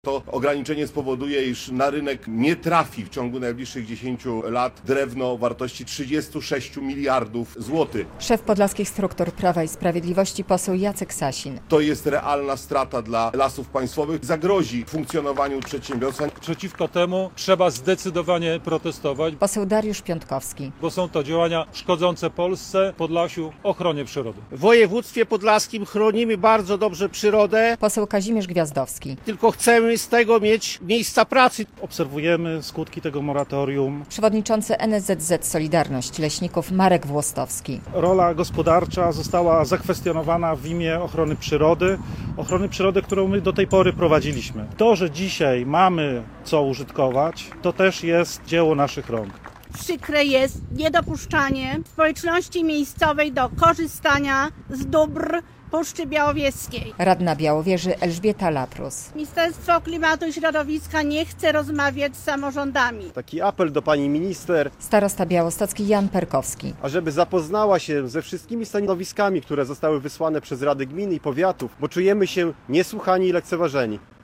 Swoje argumenty politycy PiS przedstawili na spotkaniu przed Podlaskim Urzędem Wojewódzkim w Białymstoku.